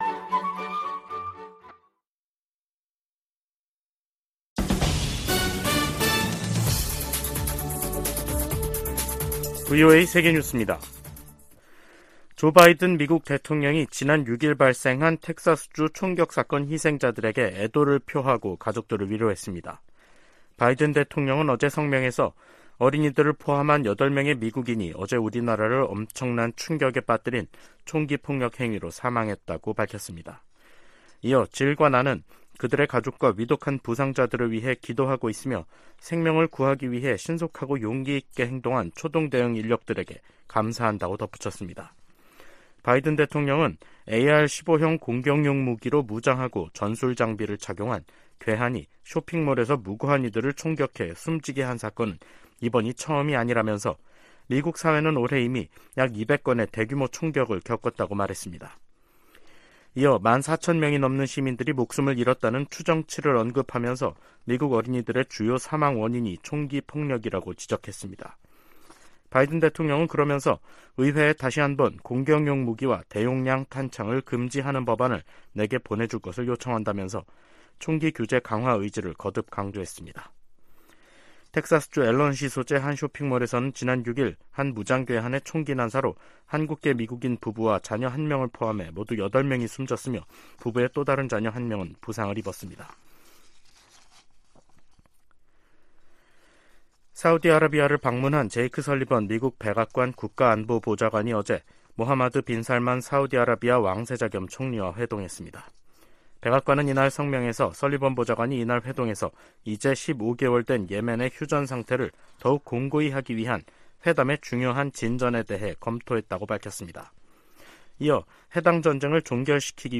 VOA 한국어 간판 뉴스 프로그램 '뉴스 투데이', 2023년 5월 8일 2부 방송입니다. 윤석열 한국 대통령과 기시다 후미오 일본 총리가 7일 북한이 제기하는 위협을 거론하며 미한일 3국 공조의 중요성을 강조했습니다.